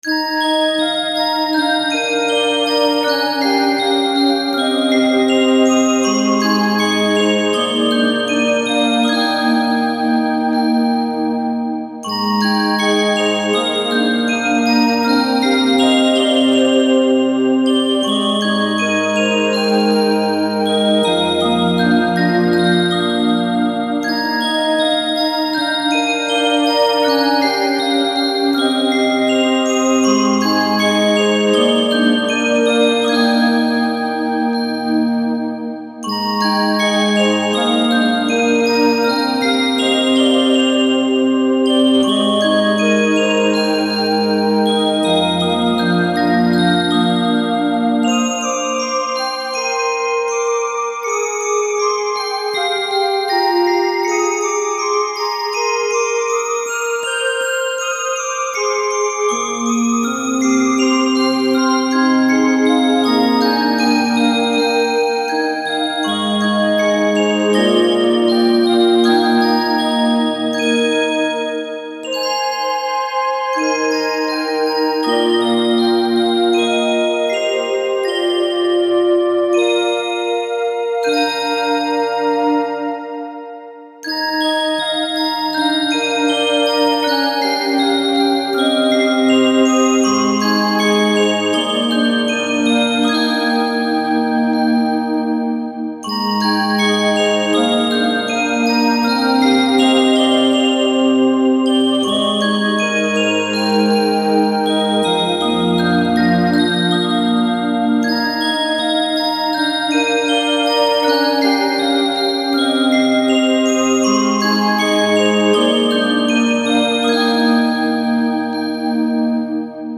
studio music
soundtrack